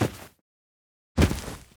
drop_2.wav